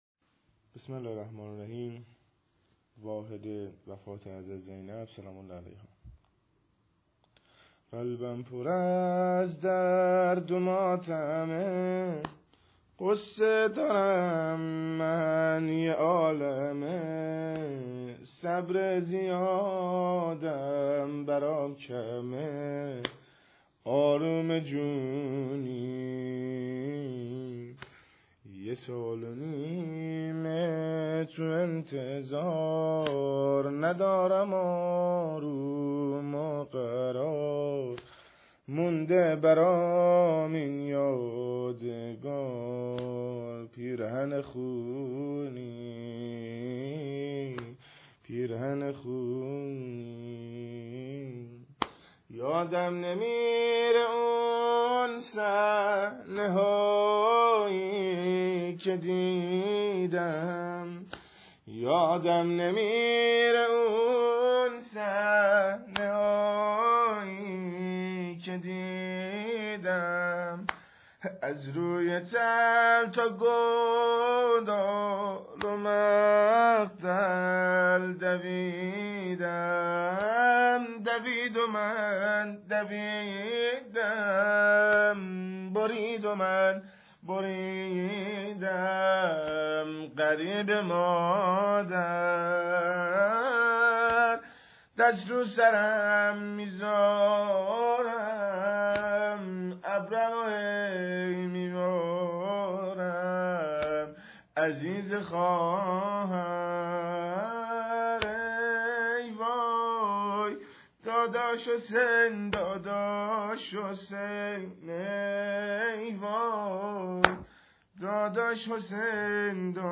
عنوان : دانلود نوحه واحد شهادت حضرت ام المصائب زینب